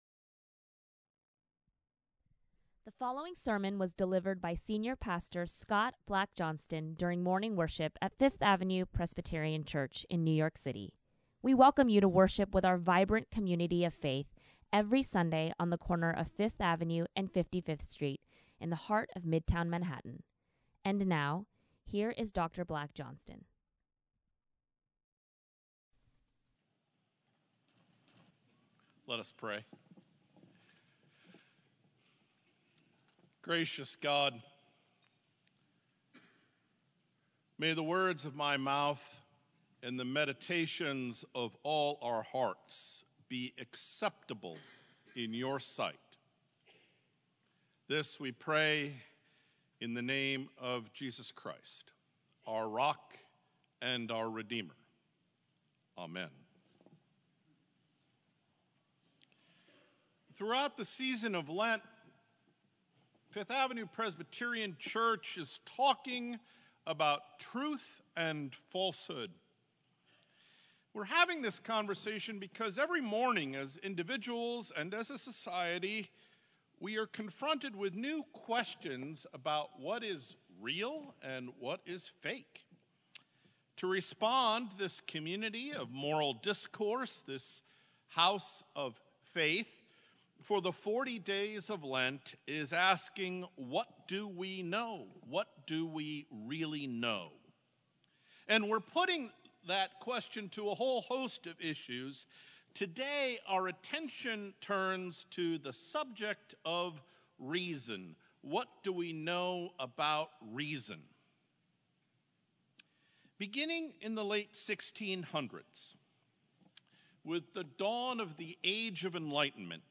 Second Sunday in Lent | Sermons at Fifth Avenue Presbyterian Church